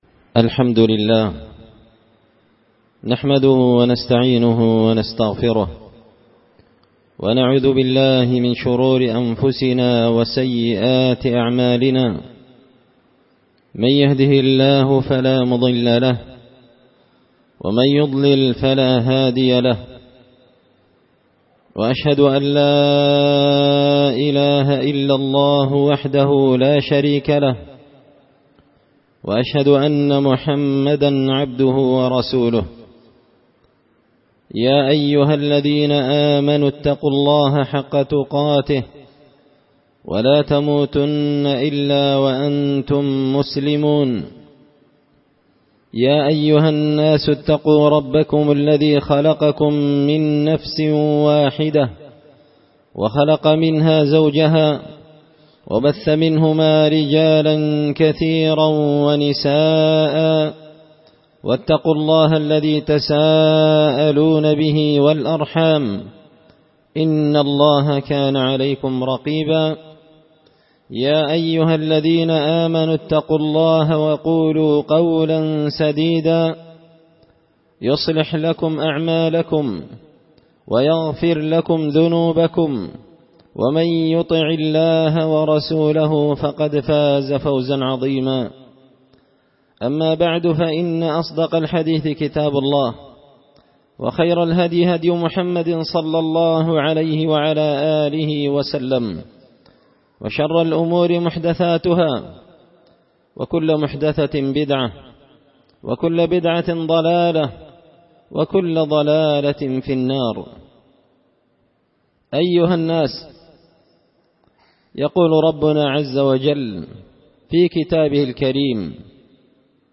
خطبة جمعة بعنوان – قصص وعبر من قصة قوم سبأ
دار الحديث بمسجد الفرقان ـ قشن ـ المهرة ـ اليمن